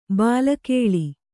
♪ bāla kēḷi